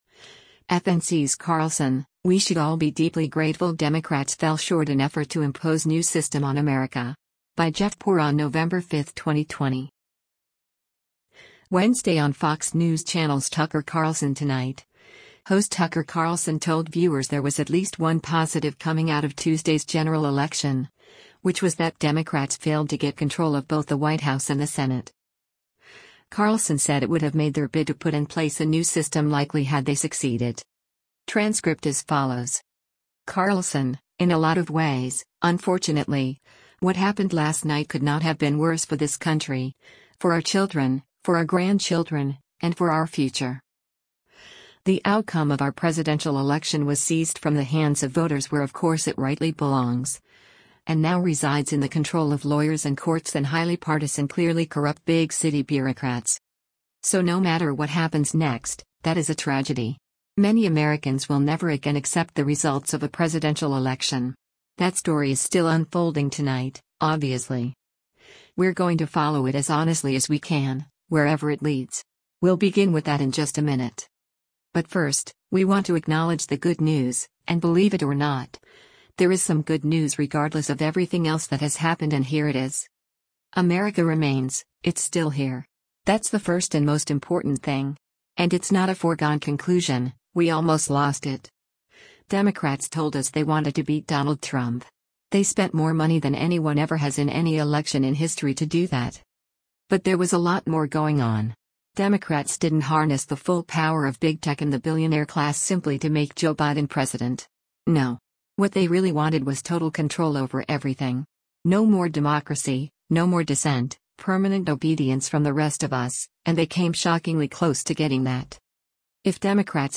Wednesday on Fox News Channel’s “Tucker Carlson Tonight,” host Tucker Carlson told viewers there was at least one positive coming out of Tuesday’s general election, which was that Democrats failed to get control of both the White House and the Senate.